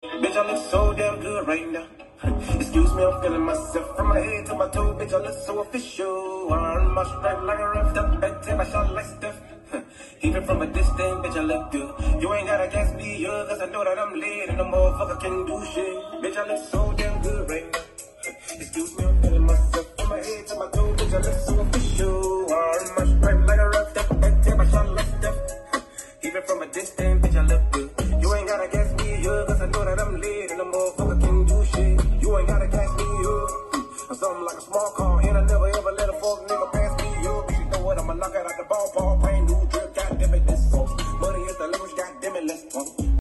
Car Audio Setup: Lightweight Truck